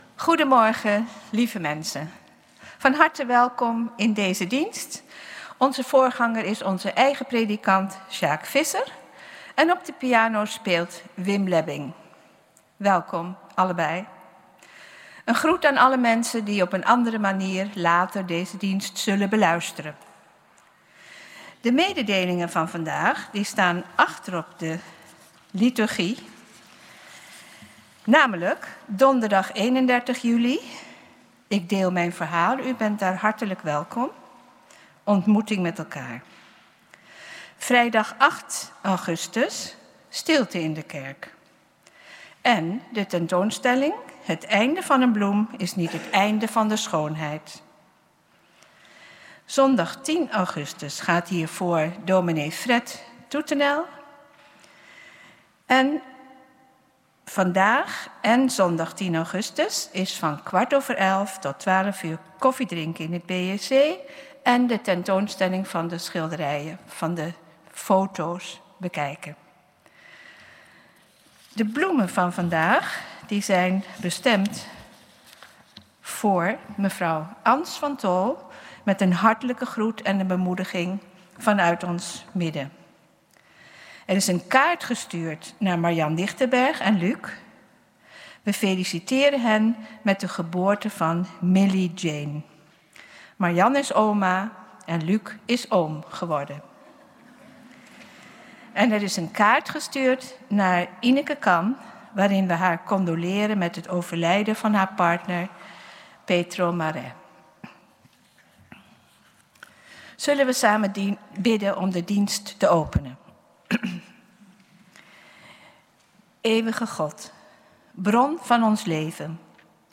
Protestantse Gemeente Oostzaan - Zondag 10.00 uur Kerkdienst in de Grote Kerk!
Kerkdienst geluidsopname